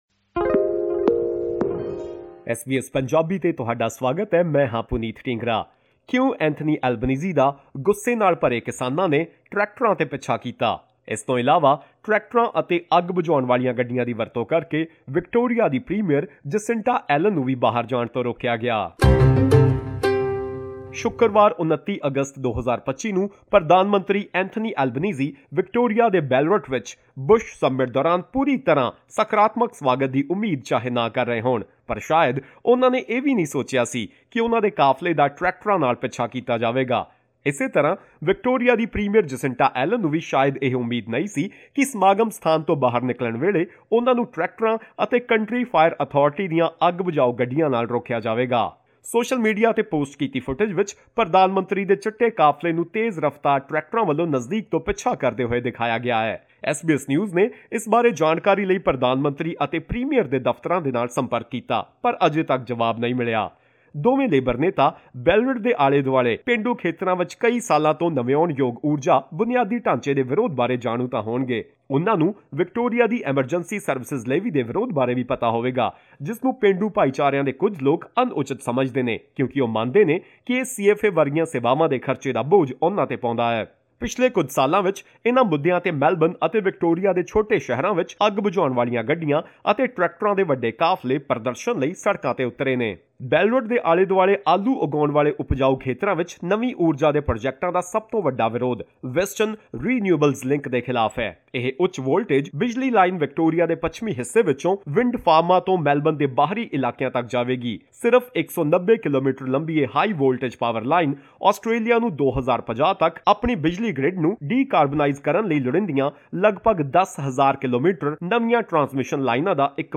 During the annual Bush Summit in Ballarat, rural Victoria, disgruntled farmers chased Prime Minister Anthony Albanese’s motorcade with tractors and blocked Premier Jacinta Allan’s exit with fire brigade trucks. The protest was primarily aimed at new energy projects and Victoria’s Emergency Services Levy. Farmers argue that the issue is not just about money but about their emotional connection to land and the environment. Listen to this full report for more details.